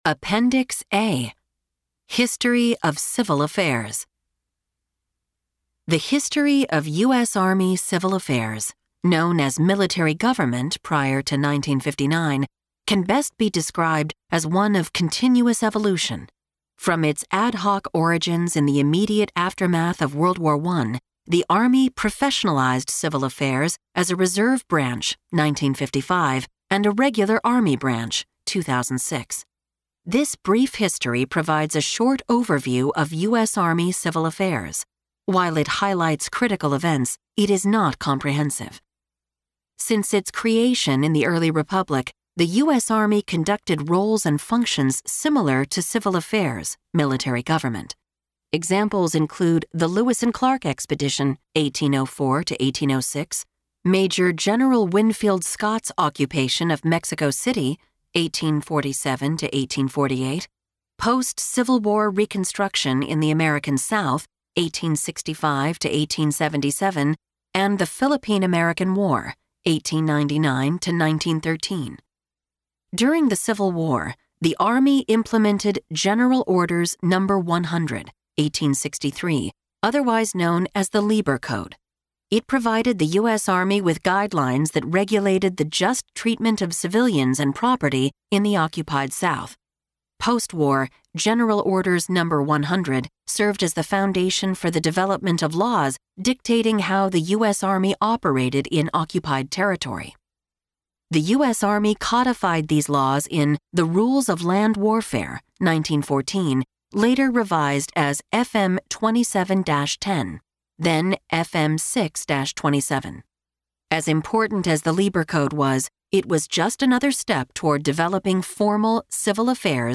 This Army Doctrine Audiobook provides users with an alternate format for accessing FM 3-57. It has been abridged to meet the requirements of the audiobook format.